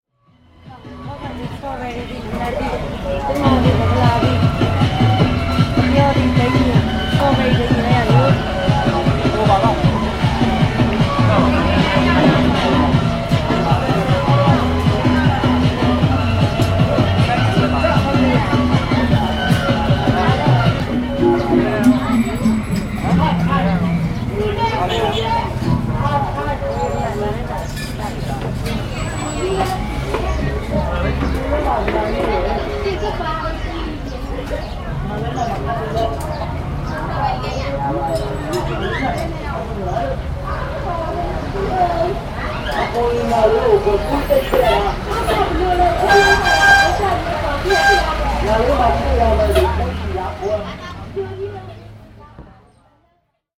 Street cafe in Yangon, Myanmar